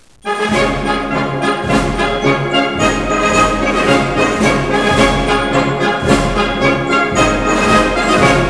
Horns
horns2.wav